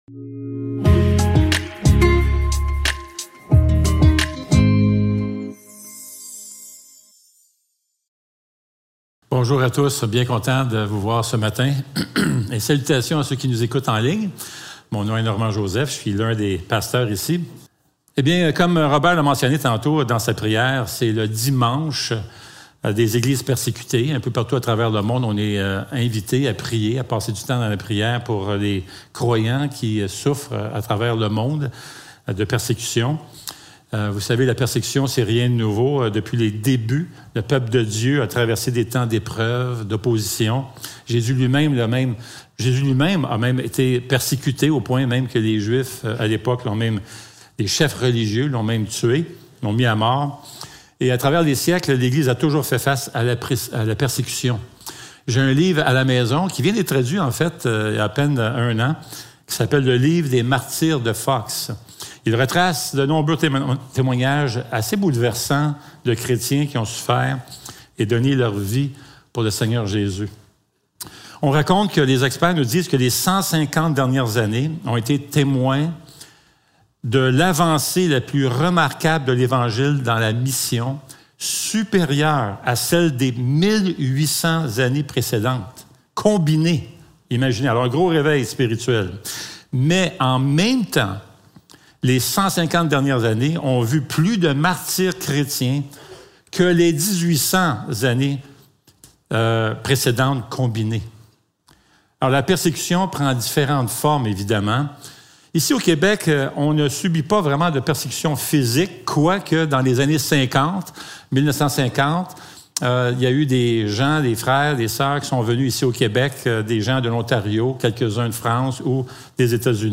Apocalypse 2.12-17 Service Type: Célébration dimanche matin Description